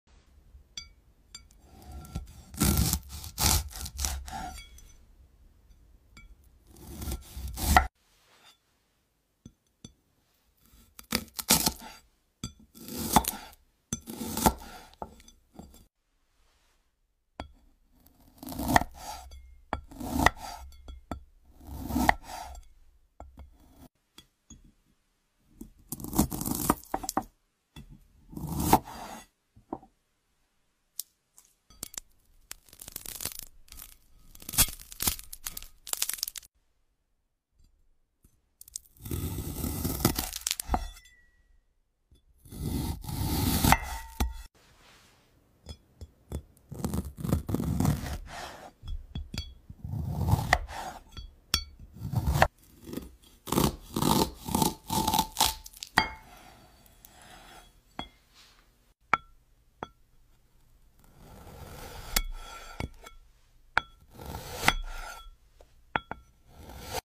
🔪 AI ASMR Glass Cutting sound effects free download
🔪 AI ASMR Glass Cutting 🔊✨ (Satisfying Sounds) Watch as AI perfectly slices through glass with crystal-clear precision